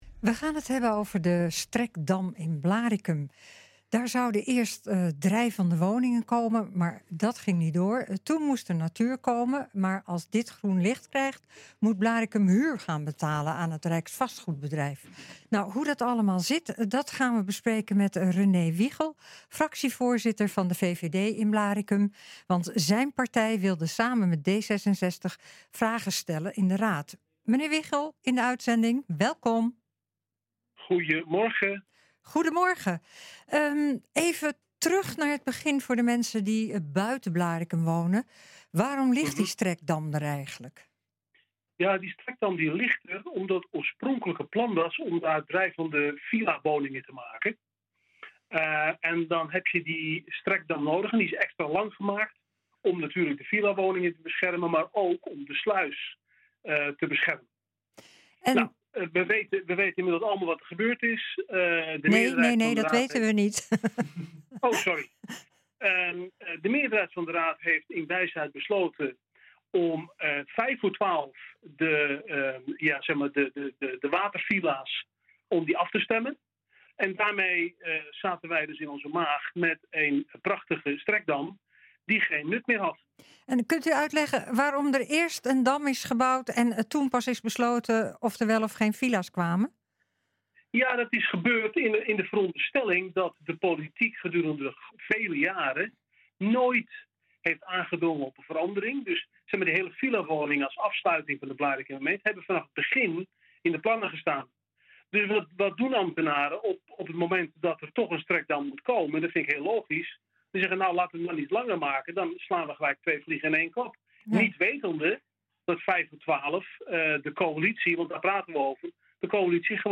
We gaan het erover hebben met Rene Wiegel, fractievoorzitter van de VVD in Blaricum, want zijn partij wilde samen met D66 vragen gesteld in de raad.